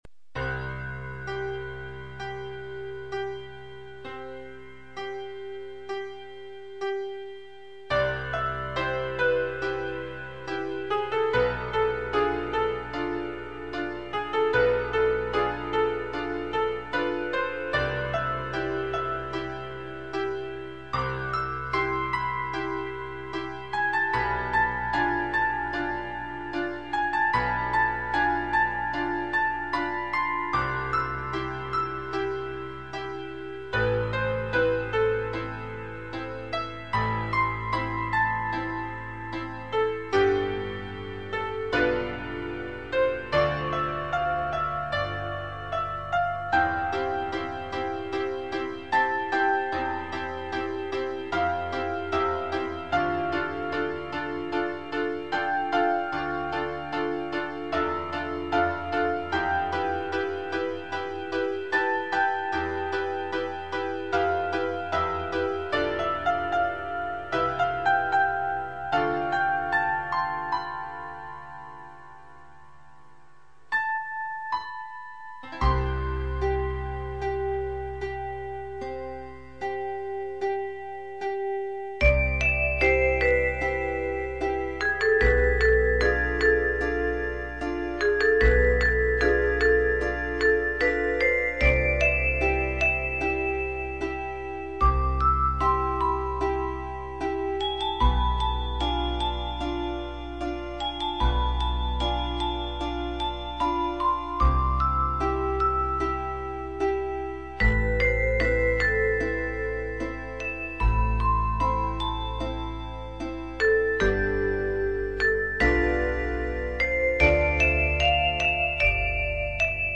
BPM=75
POINT ピアノのシンプルな曲です。 Aメロを高い音で繰り返すところとか気に入ってますね。
明るい 遅い